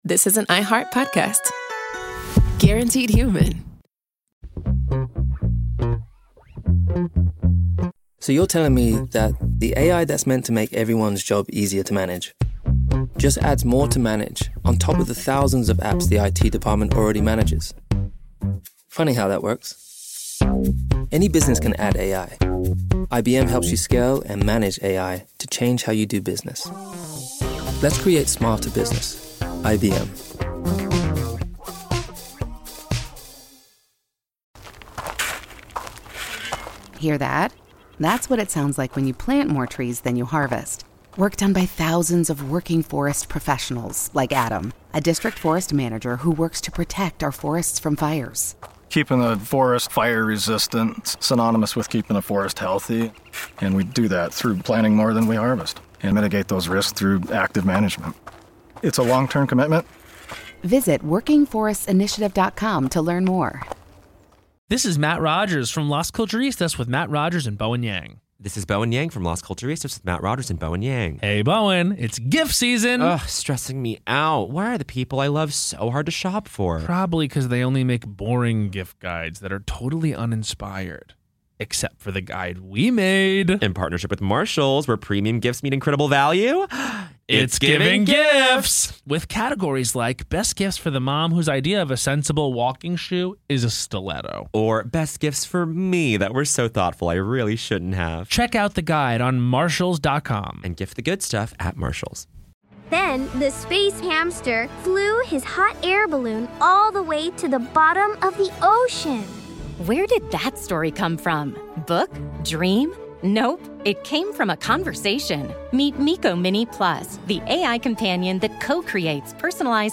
Each call traces the shape of faith, regret, and forgiveness inside a place built for punishment.